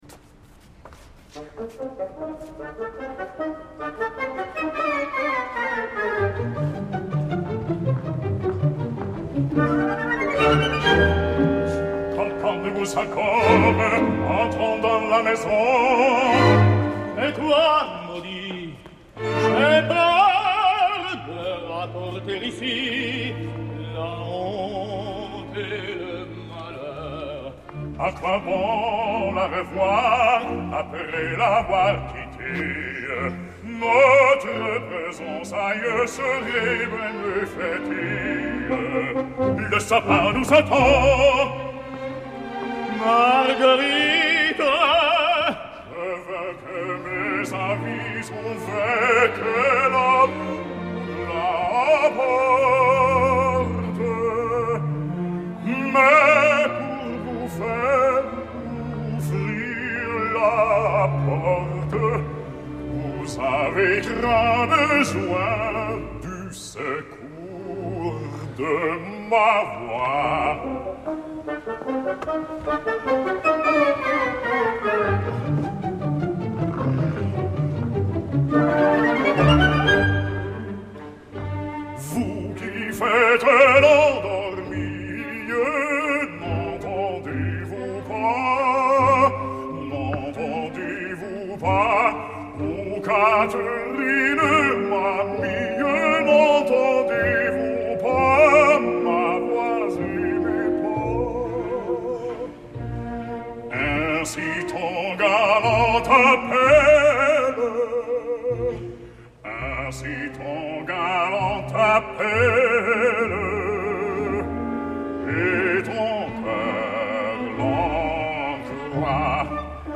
bass
serenata